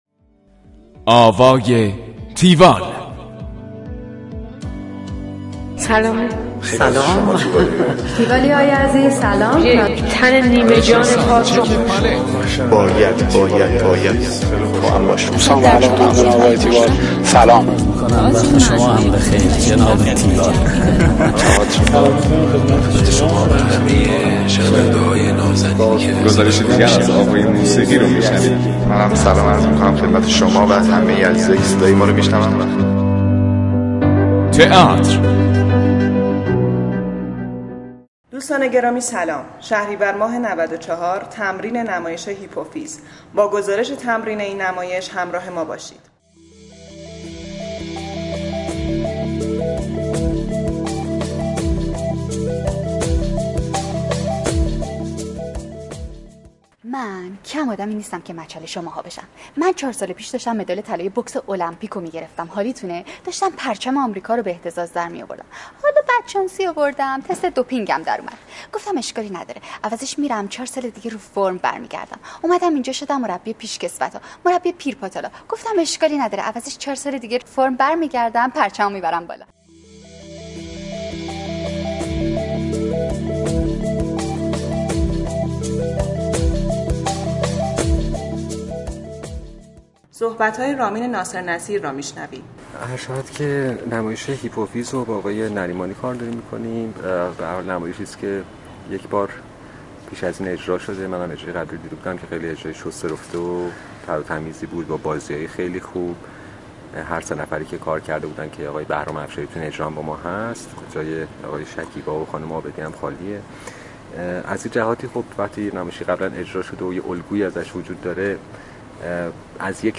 گزارش آوای تیوال از نمایش هیپوفیز